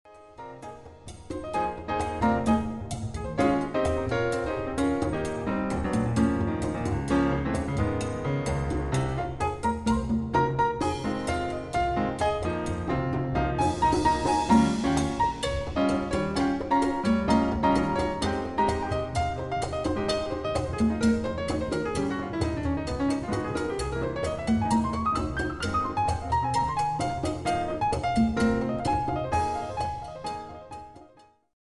Percussion
Piano
Bass